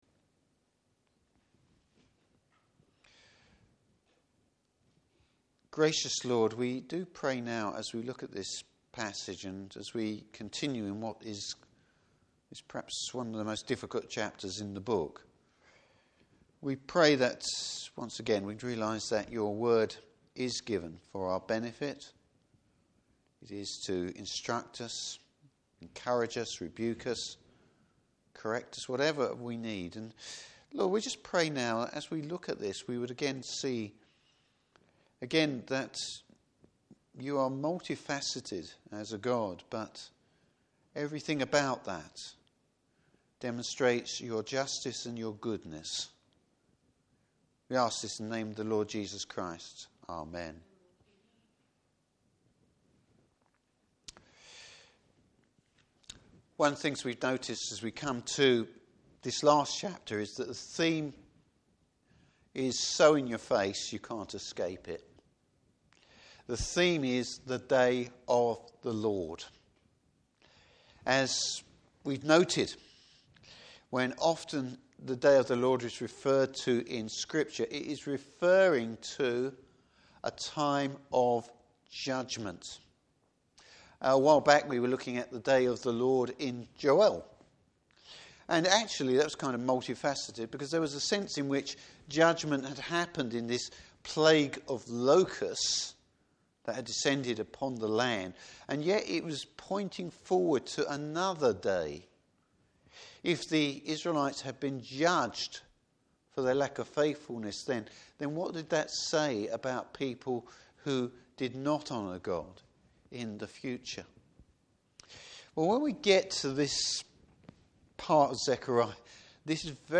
Service Type: Evening Service The other side of the Lord’s coming!